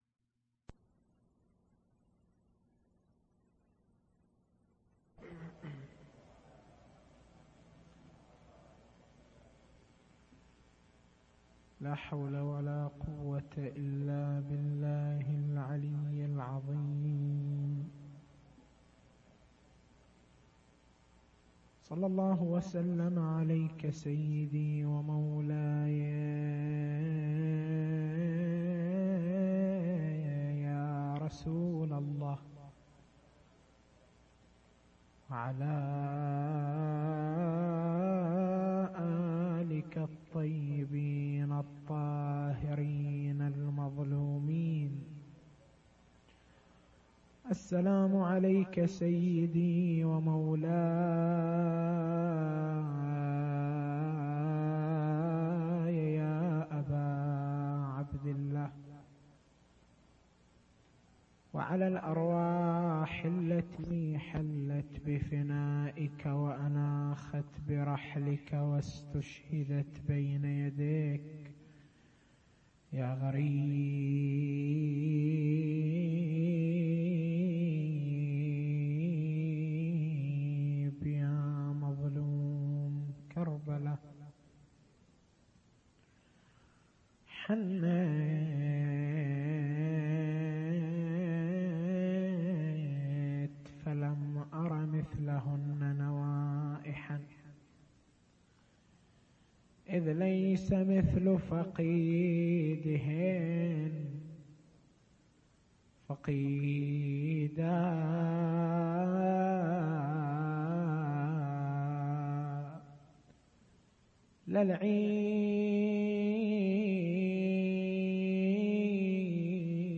تاريخ المحاضرة: 26/09/1418